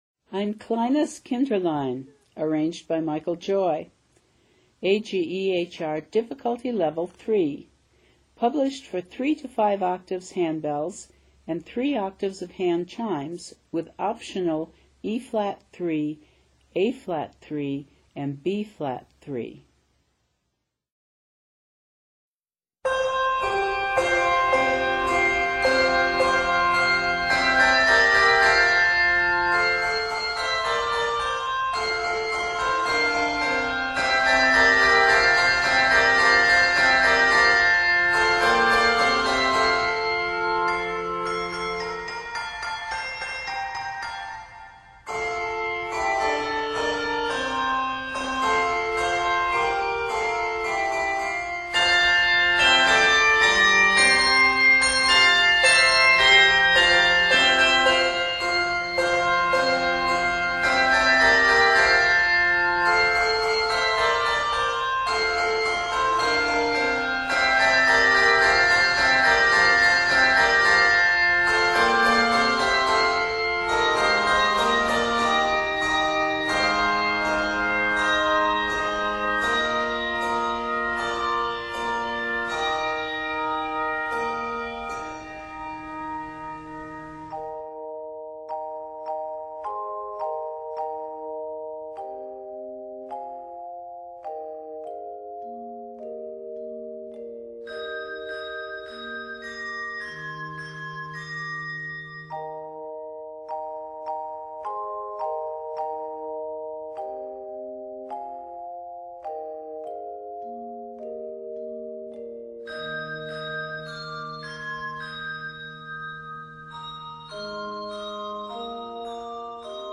The energetic outer sections of the piece
Set in F Major and Eb Major, this piece is 75 measures.
Octaves: 3-5